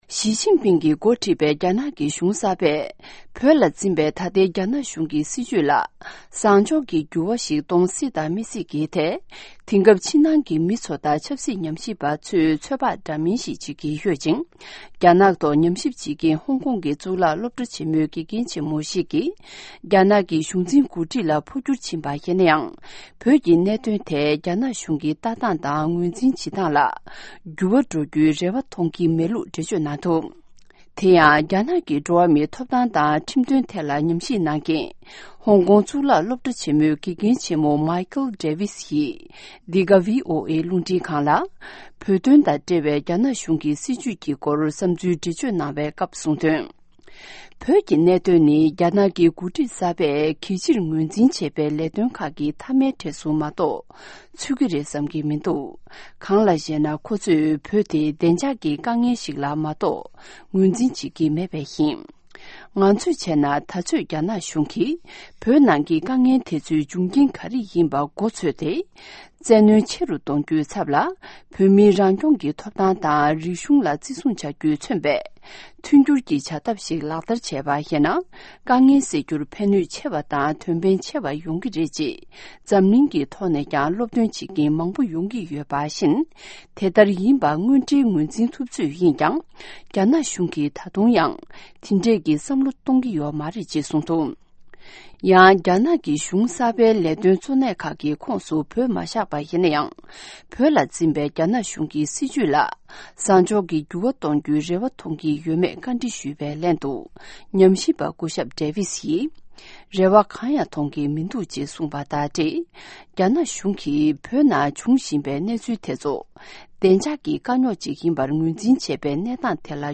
བོད་དང་འབྲེལ་བའི་རྒྱ་ནག་གི་སྲིད་དོན་སྐོར་འདྲི་བ་དྲི་ལན།